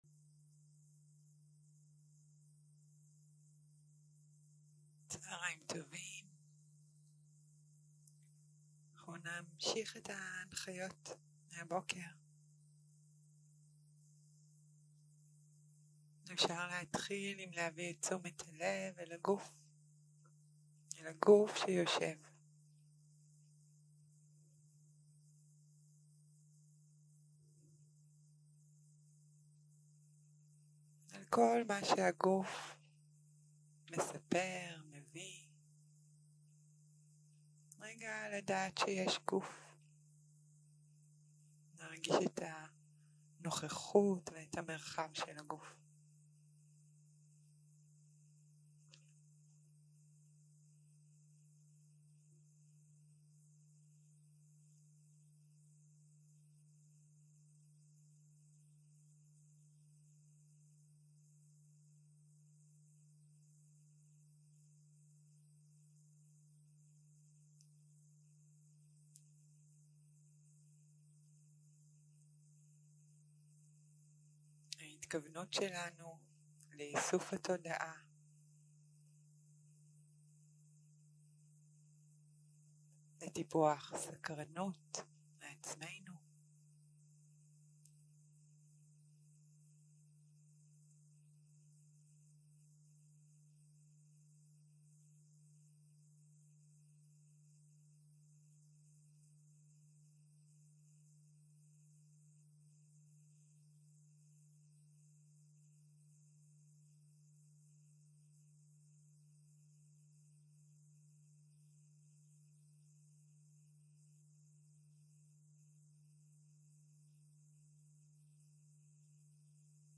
יום 2 - צהרים - מדיטציה מונחית - איסוף התודעה לנשימה, תשומת לב לנשימה - הקלטה 3 0:00 0:00 סוג ההקלטה: סוג ההקלטה: מדיטציה מונחית שפת ההקלטה: שפת ההקלטה: עברית